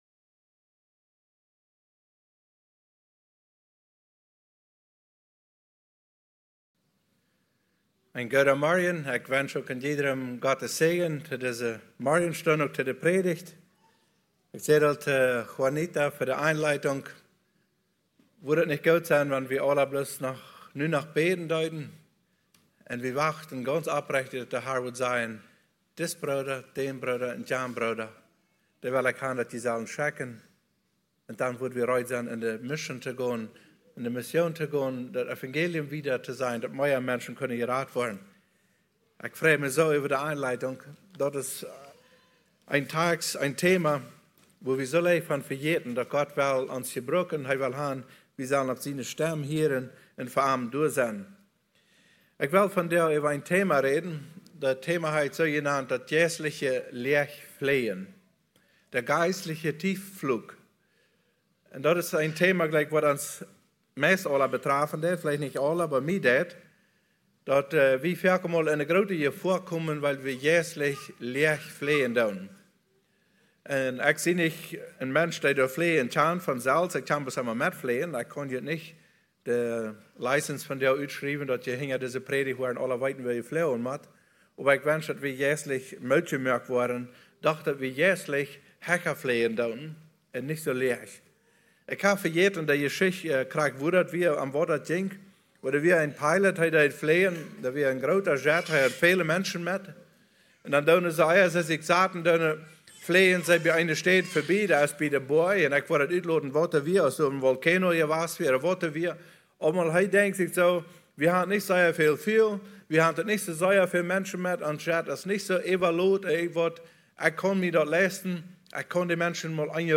Sonntagspredigt - Sermon